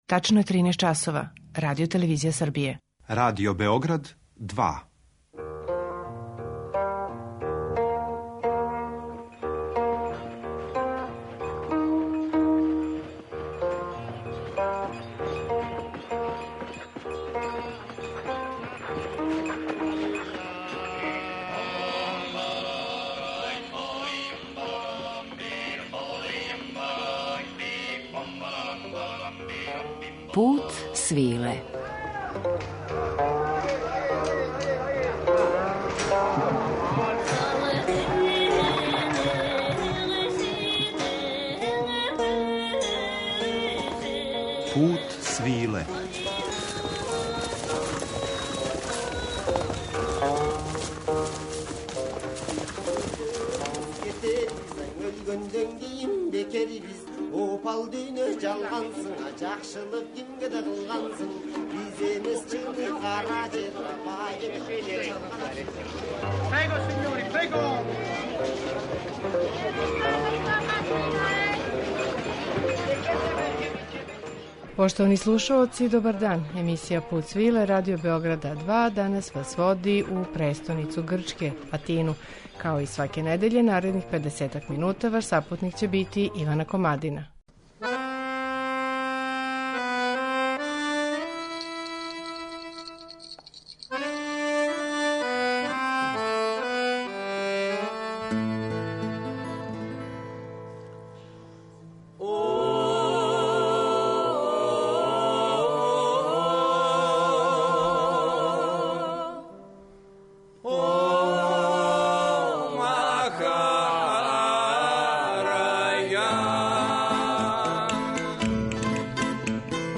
Престоницу Грчке обићи ћемо у музичком друштву атинске групе Компаниа.
Престоницу Грчке обићи ћемо у музичком друштву атинске групе Компаниа , која негује два стара стила грчке градске музике: ребетико и смирнеико.